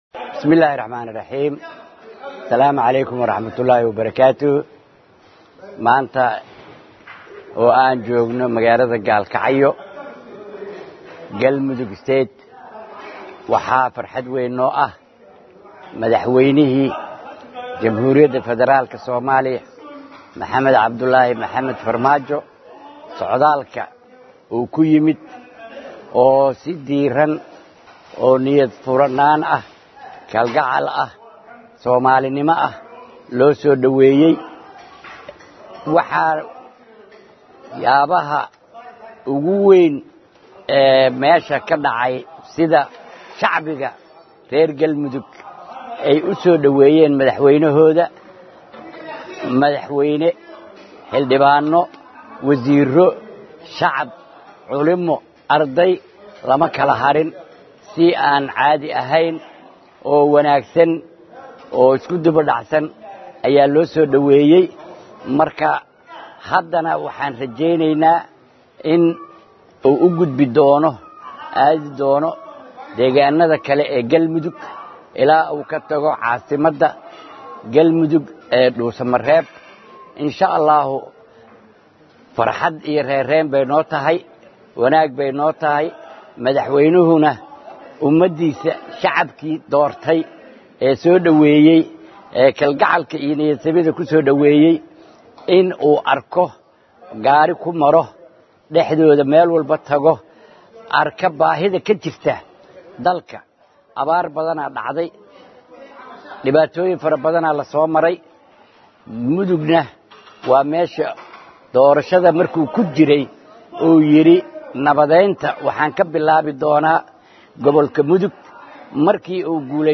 Madaxweynihii hore Galmudug Maxamed Axmed Caalin ayaa ka hadlay safarka madaweyne Farnaajo deegaanada Galmudug uu xiligan ku marayo,